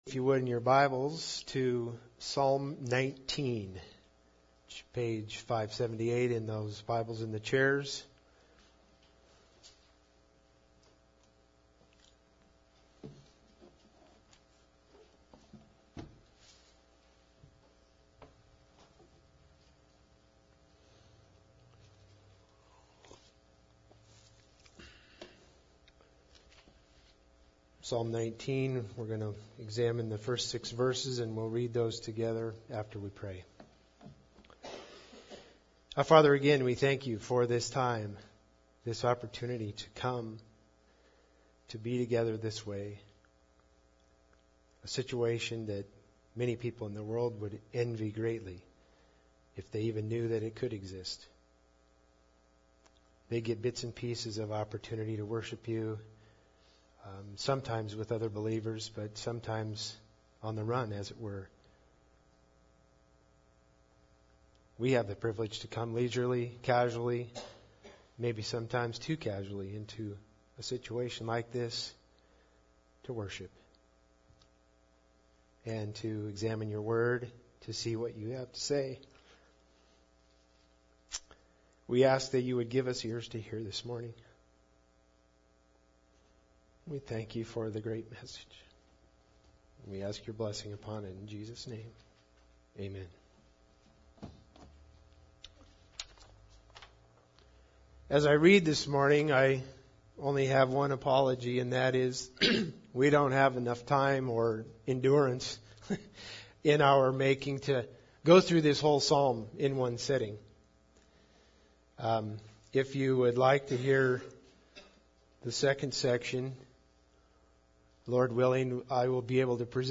Psalms 19:1-6 Service Type: Sunday Service Bible Text